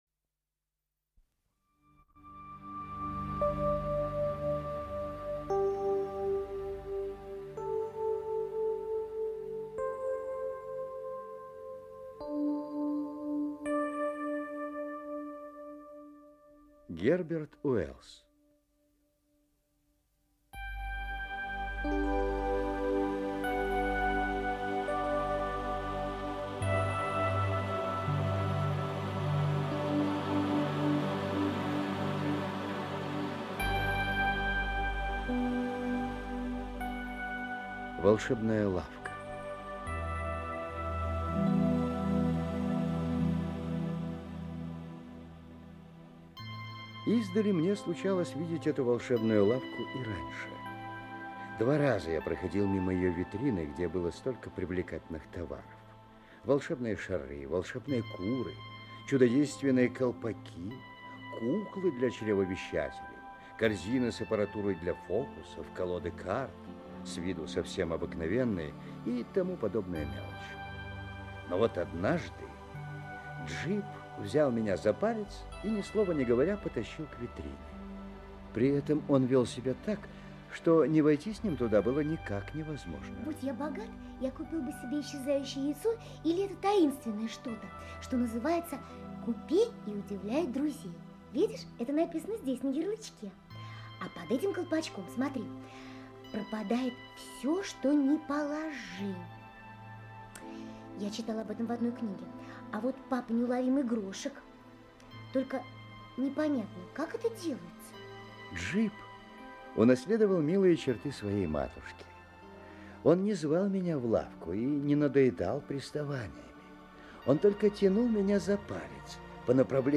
Аудиокнига Волшебная лавка | Библиотека аудиокниг